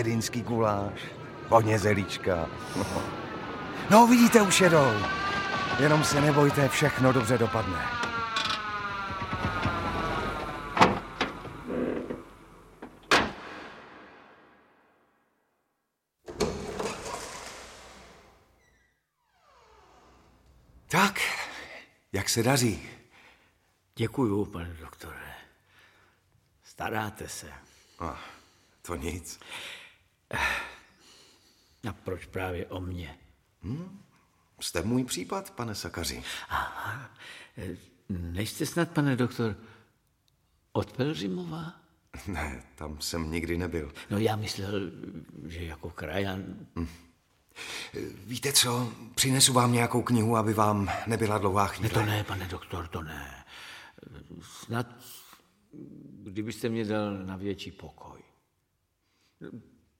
Audiobook
Read: Jan Šťastný